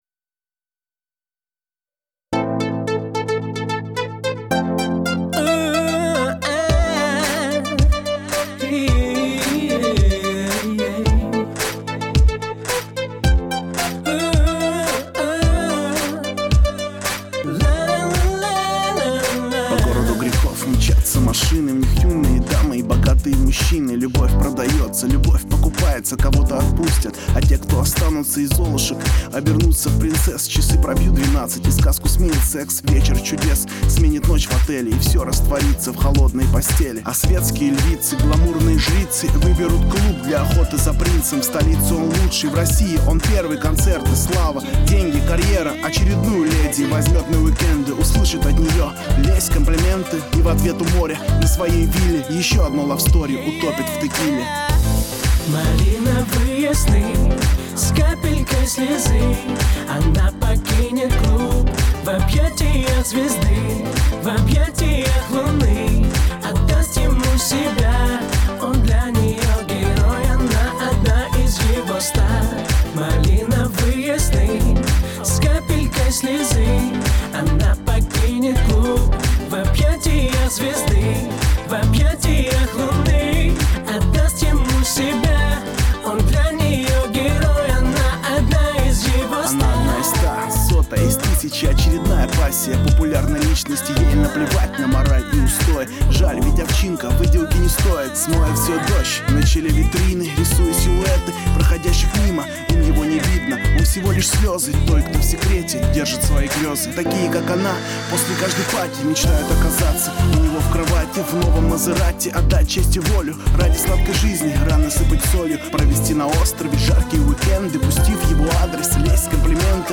Жанр: Красивая музыка